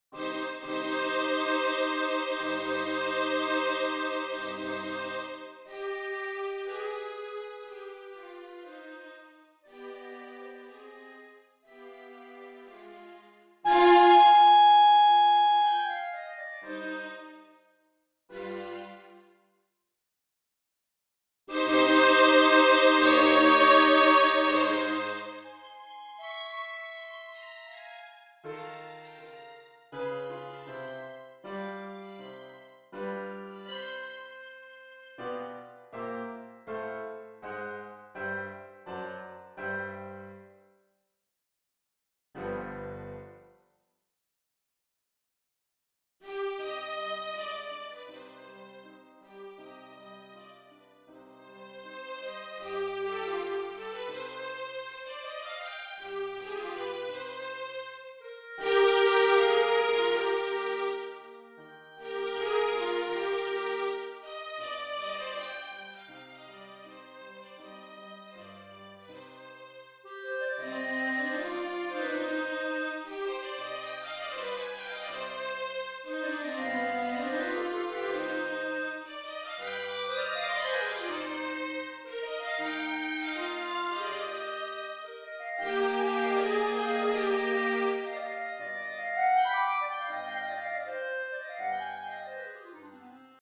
Klarinette, Violine und Klavier